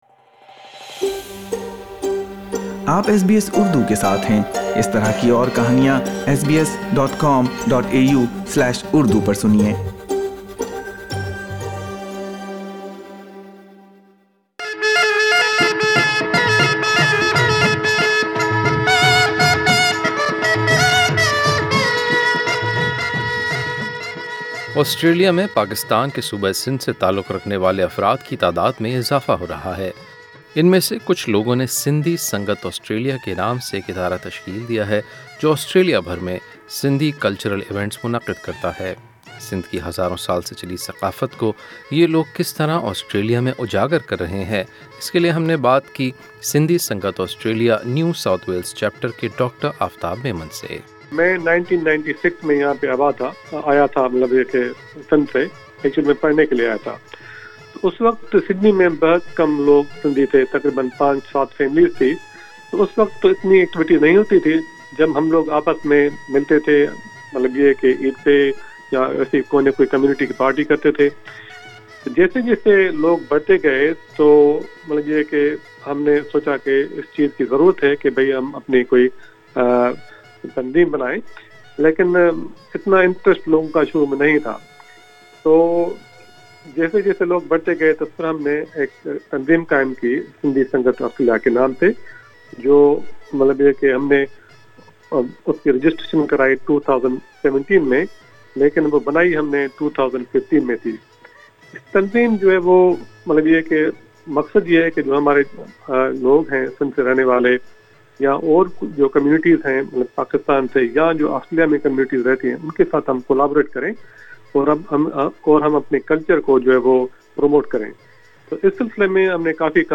گفتگو۔